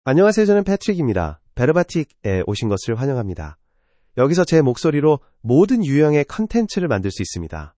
PatrickMale Korean AI voice
Patrick is a male AI voice for Korean (Korea).
Voice sample
Male
Patrick delivers clear pronunciation with authentic Korea Korean intonation, making your content sound professionally produced.